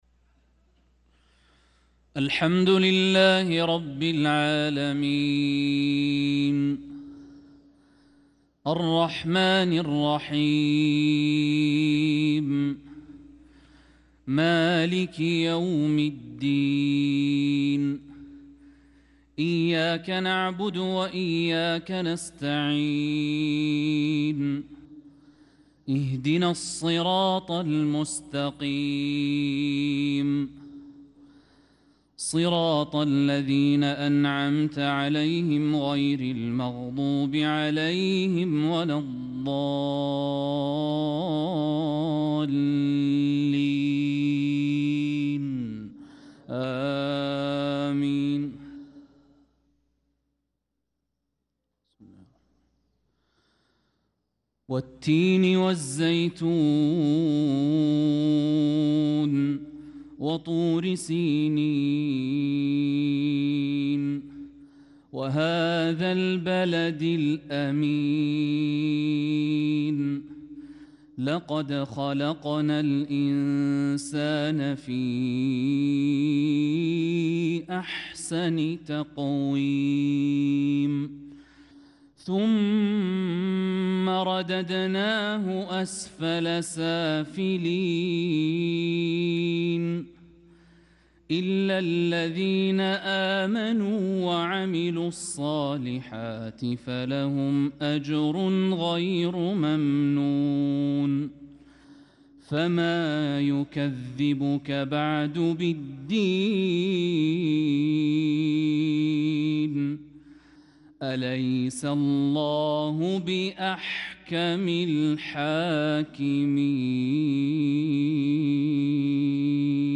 صلاة المغرب للقارئ الوليد الشمسان 27 ربيع الآخر 1446 هـ
تِلَاوَات الْحَرَمَيْن .